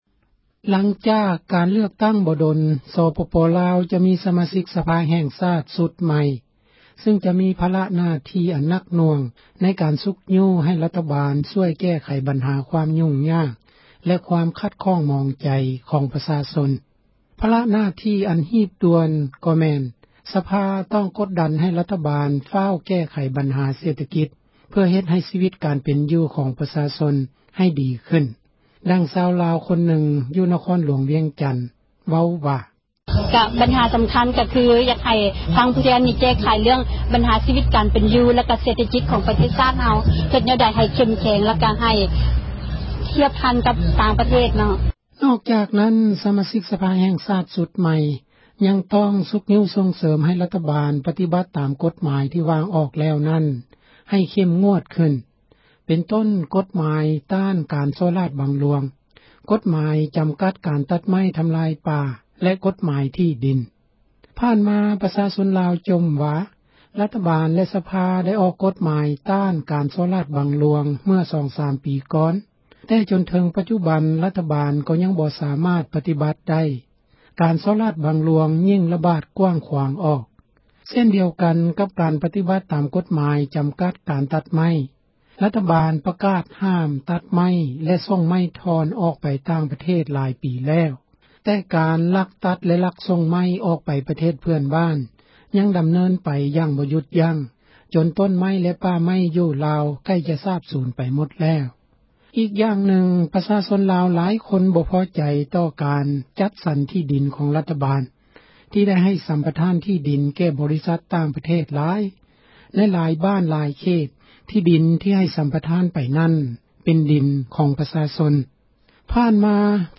ຫລັງຈາກ ການເລືອກຕັ້ງ ບໍ່ດົນ ສປປລາວ ຈະມີ ສະມາຊິກ ສະພາ ແຫ່ງຊາດ ຊຸດໃໝ່ ຊຶ່ງຈະມີ ພາລະ ໜ້າທີ່ ອັນໜັກໜ່ວງ ໃນການ ຊຸກຍູ້ ໃຫ້ ຣັຖບານ ແກ້ໄຂ ບັນຫາ ຄວາມຍຸ້ງຍາກ ແລະ ຄວາມຂັດຂ້ອງ ໝອງໃຈ ຂອງ ປະຊາຊົນ. ພາລະ ໜ້າທີ່ ອັນຮີບດ່ວນ ກໍແມ່ນ ສະພາ ຕ້ອງກົດດັນ ໃຫ້ຣັຖບານ ຟ້າວແກ້ໄຂ ບັນຫາ ເສຖກິດ ເພື່ອເຮັດໃຫ້ ຊີວິດ ການເປັນຢູ່ ຂອງ ປະຊາຊົນ ໃຫ້ດີຂື້ນ ດັ່ງຊາວລາວ ຄົນນຶ່ງ ຢູ່ນະຄອນຫລວງ ວຽງຈັນ ເວົ້າວ່າ: